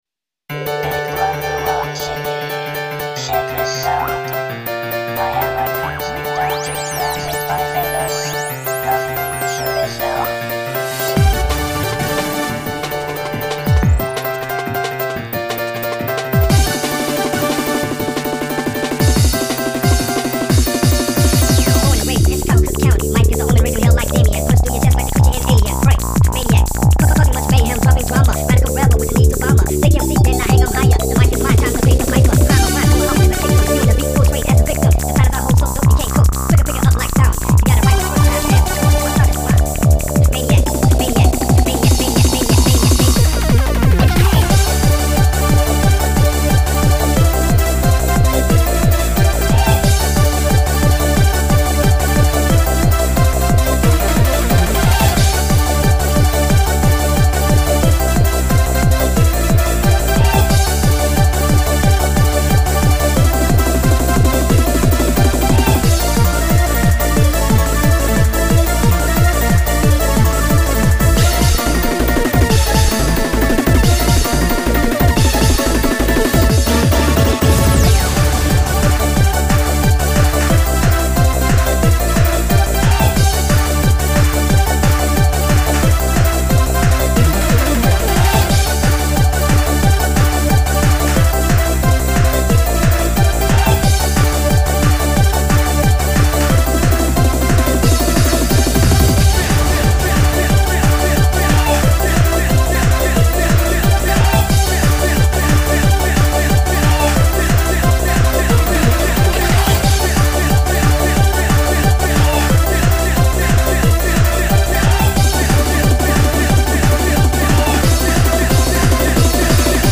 MCK
ModPlug Tracker(j)
アレンジ内容ですが、聴いての通りファミコン風味です。
ファミコンのPSGは結構ノイジーなので、主旋律（矩形波パート）は
soundforgeでステレオ化した後にリバーブ掛けてます。